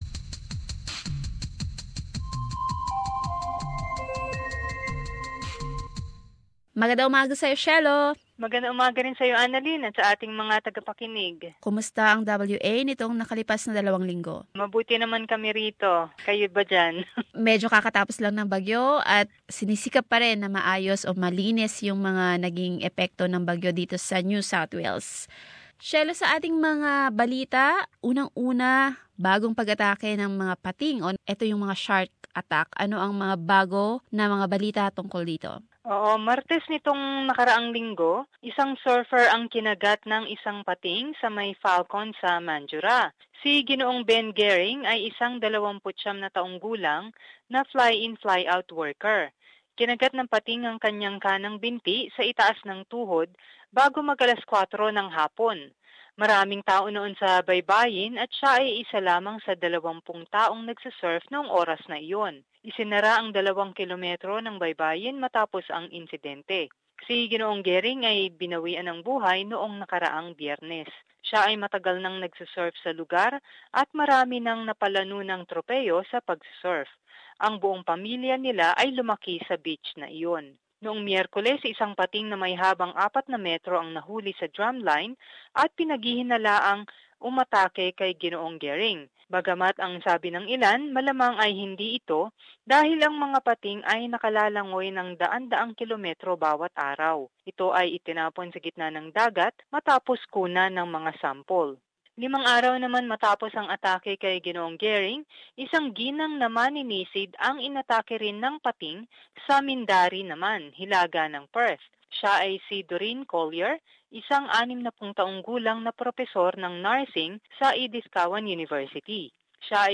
Perth report.